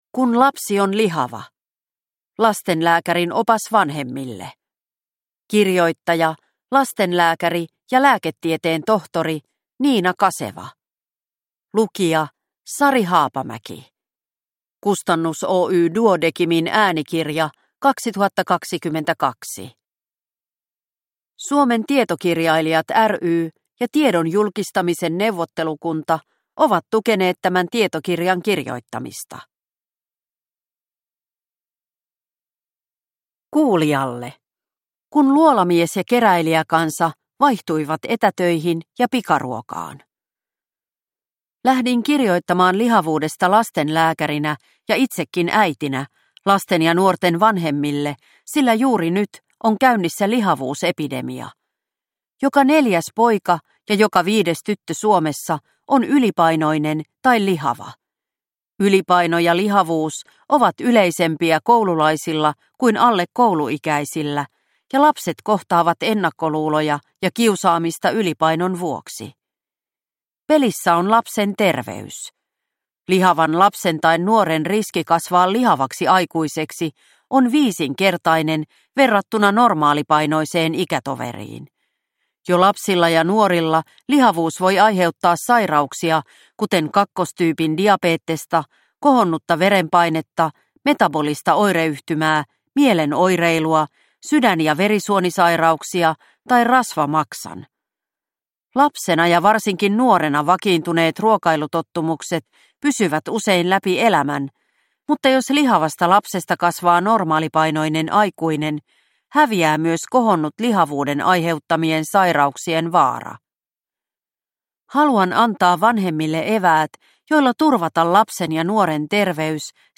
Kun lapsi on lihava – Ljudbok – Laddas ner